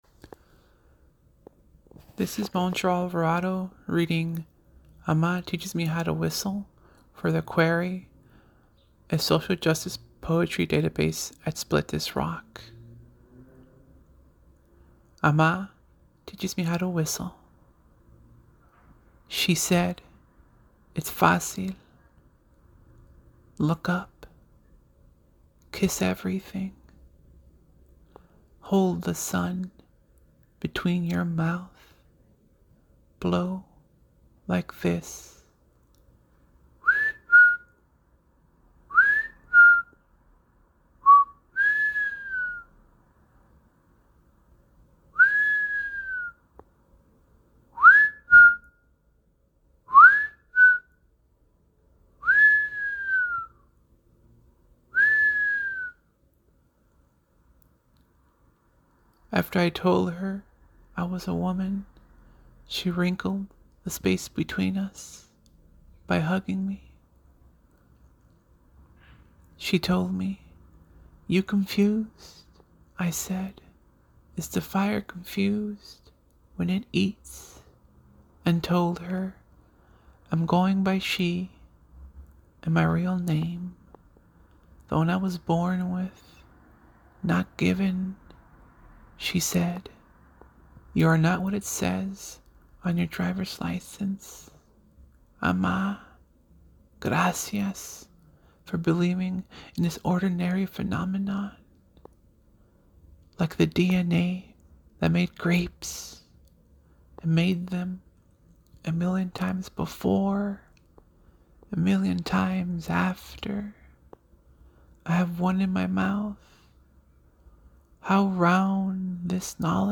Sunday Poem
Vocal rendering by author>